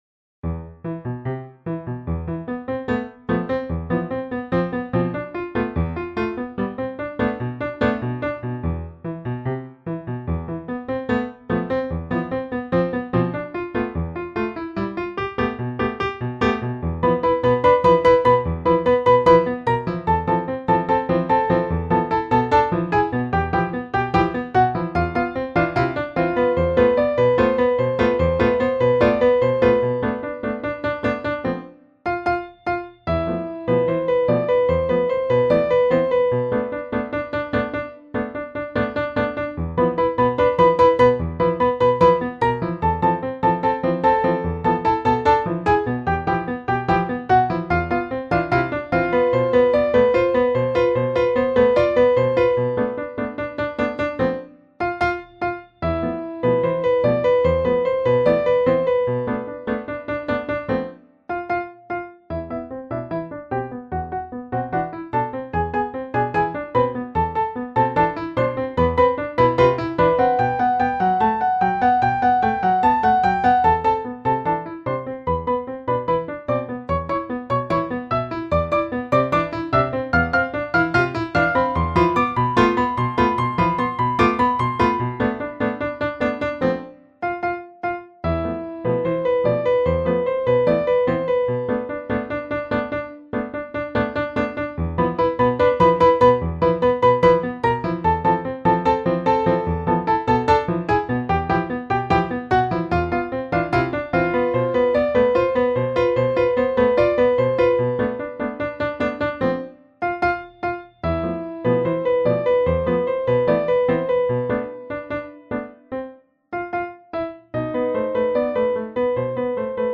Piano duet